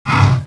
Heroes3_-_Green_Dragon_-_HurtSound.ogg